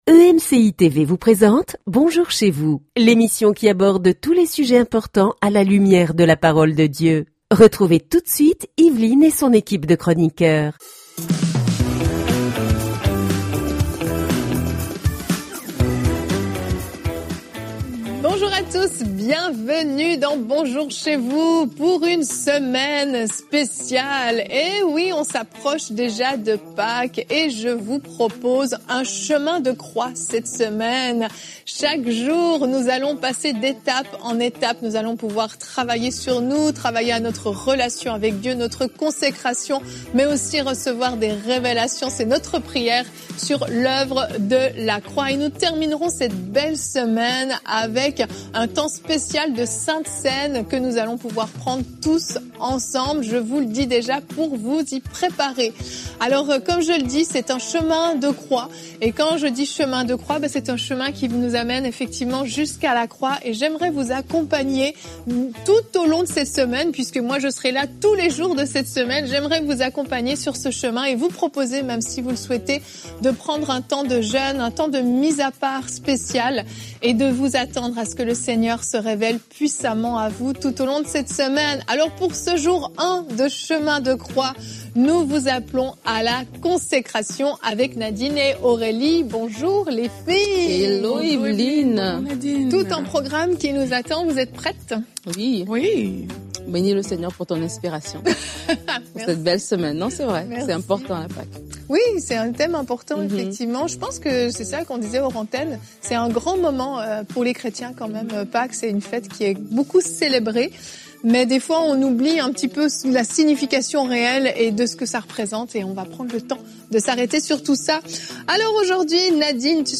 Bonjour Chez Vous est une émission matinale fraiche et moderne
Vous serez édifiés, encouragés et informés sur l'actualité musicale, cinématographique et culturelle chrétienne. Dynamisme et bonne humeur sont au rendez-vous !